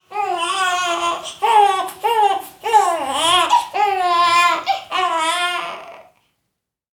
دانلود آهنگ گریه نوزاد 1 از افکت صوتی انسان و موجودات زنده
دانلود صدای گریه نوزاد 1 از ساعد نیوز با لینک مستقیم و کیفیت بالا
جلوه های صوتی